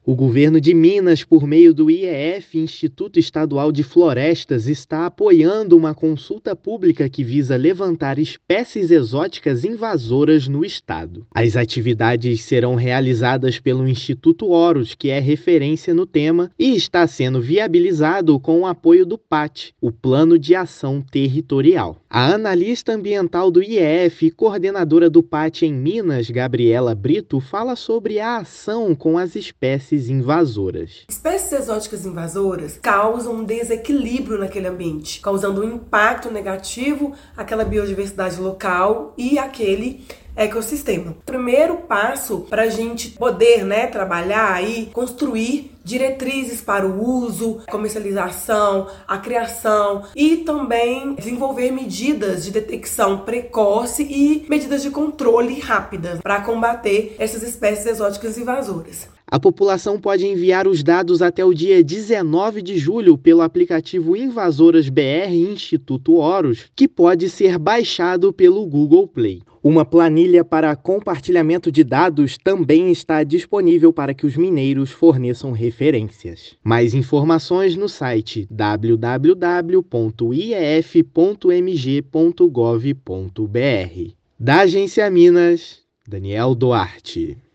[RÁDIO] Governo apoia consulta pública para levantamento de espécies exóticas invasoras no estado
Resultado final do levantamento embasará a elaboração da Lista de Espécies Exóticas Invasoras. Ouça a matéria de rádio: